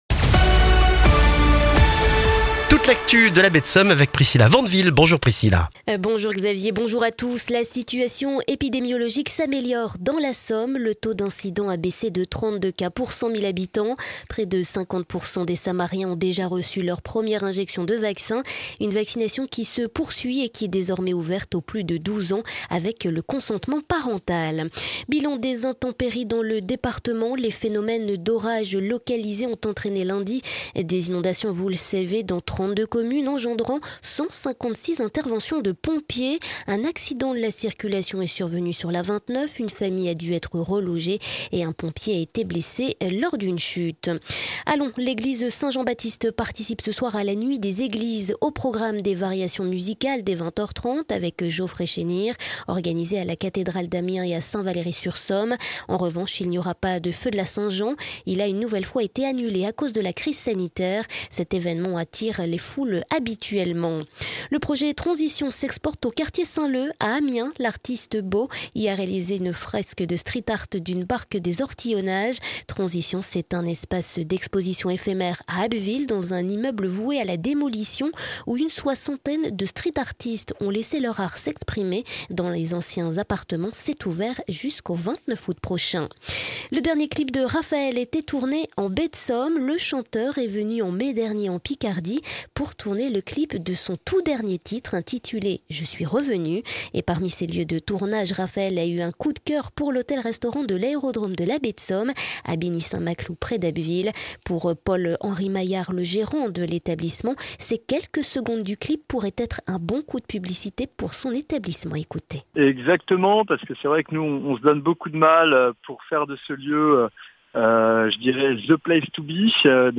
Le journal du mercredi 23 juin en Baie de Somme et dans la région d'Abbeville